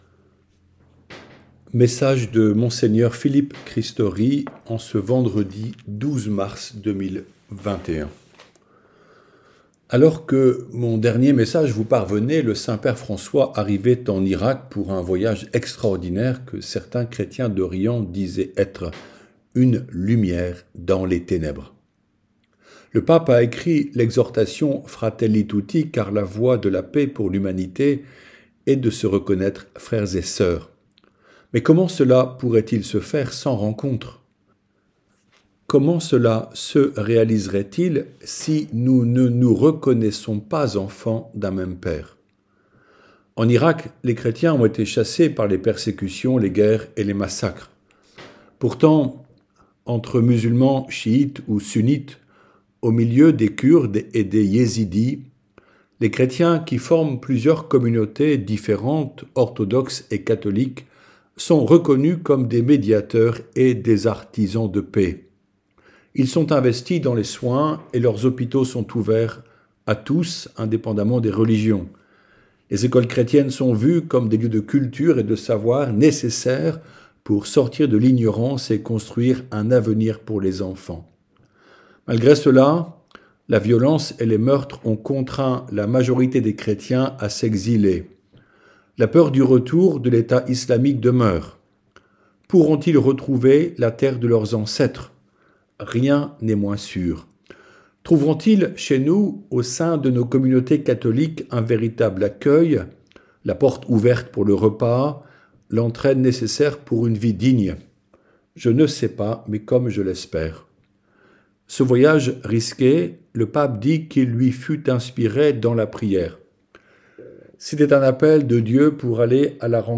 Message de Monseigneur Philippe Christory vendredi 12 mars